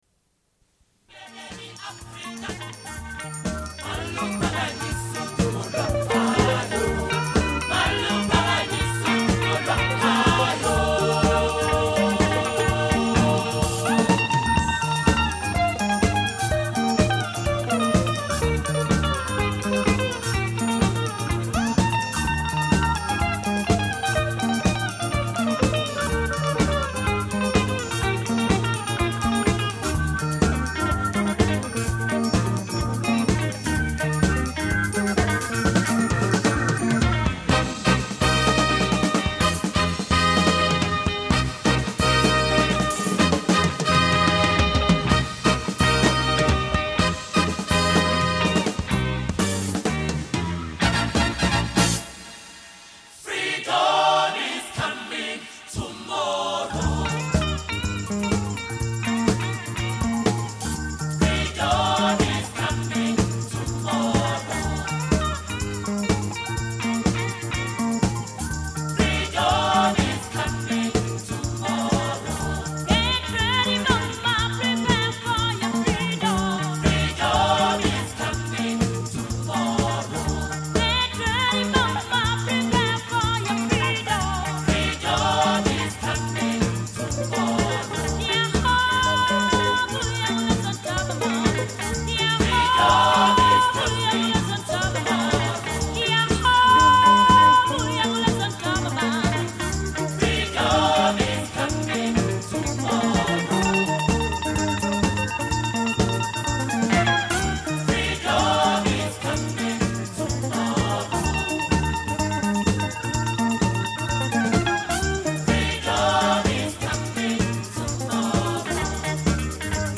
Une cassette audio, face A31:43